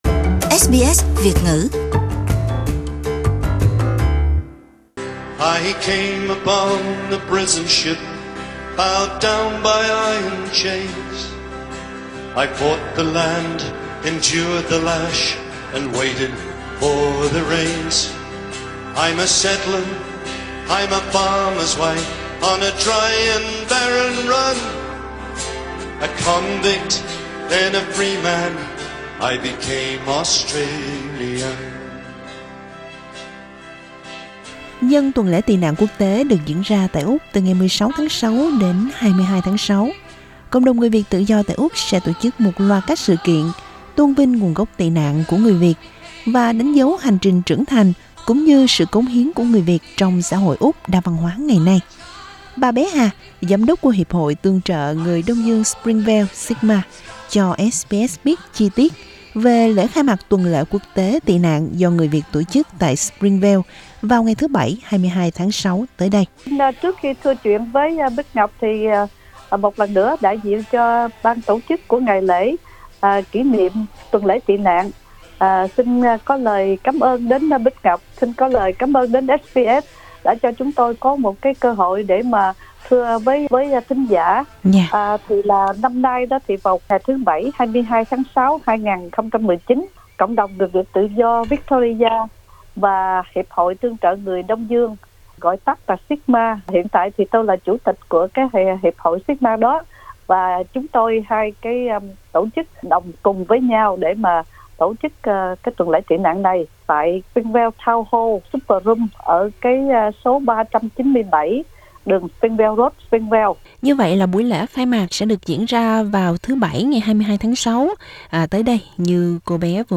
"Van nho ngay ve" live music night in Springvale on 22 June Source: Supplied Mời bấm vào audio để nghe nguyên văn phỏng vấn.